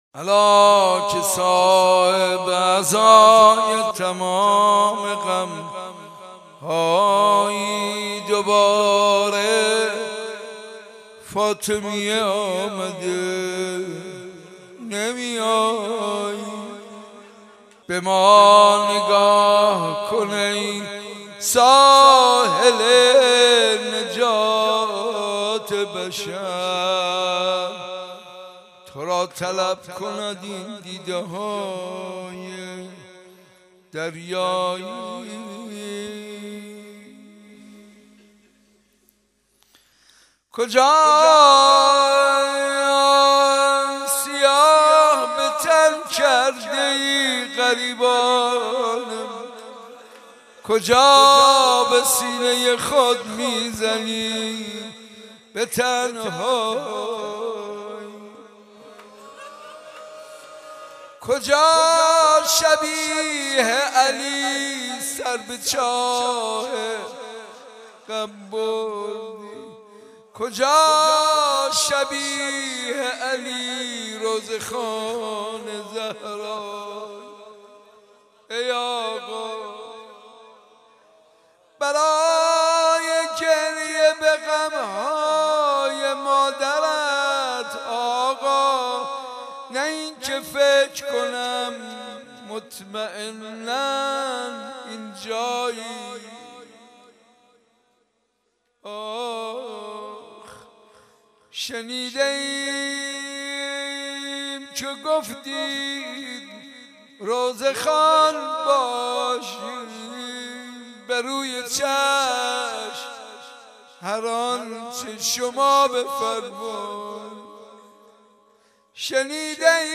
مراسم زیارت عاشورا روز چهارم فاطمیه اول حسینیه صنف لباس فروشان ساعت 6 صبح با سخنرانی حجت الاسلام و المسلمین